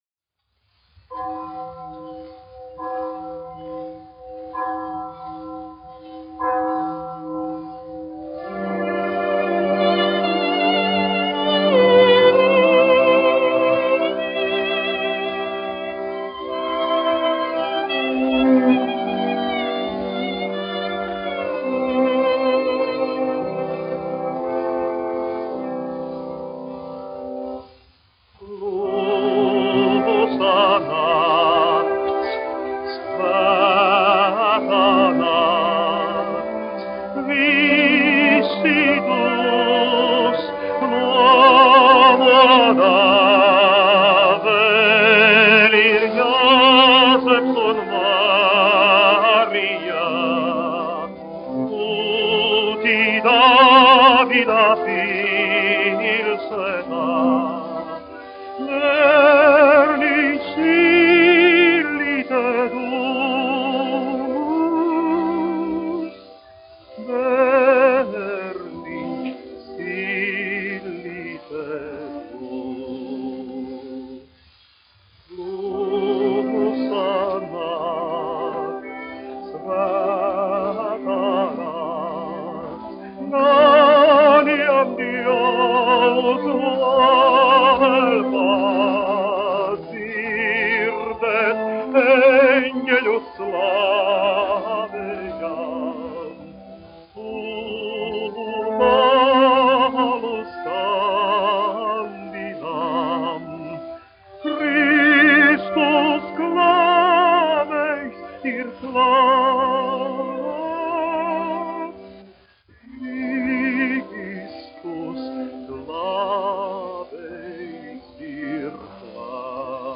Mariss Vētra, 1901-1965, dziedātājs
1 skpl. : analogs, 78 apgr/min, mono ; 25 cm
Ziemassvētku mūzika
Latvijas vēsturiskie šellaka skaņuplašu ieraksti (Kolekcija)